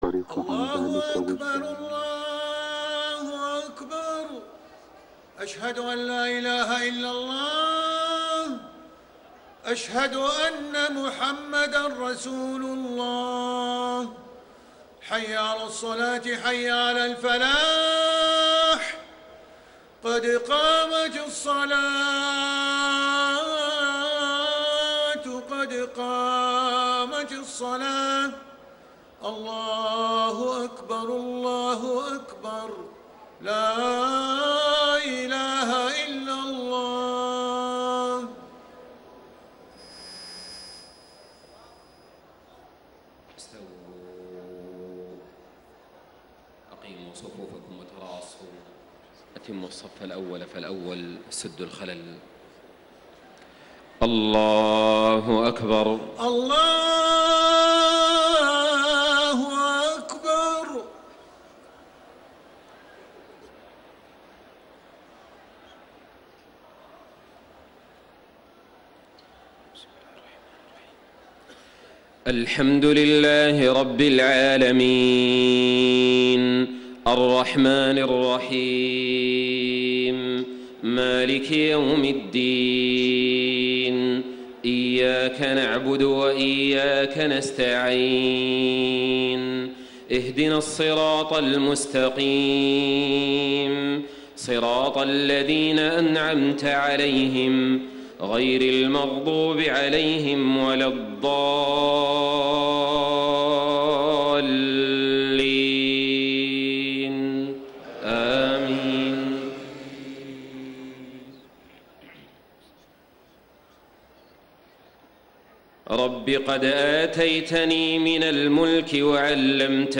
صلاة العشاء 4-4-1435هـ من سورة يوسف > 1435 🕋 > الفروض - تلاوات الحرمين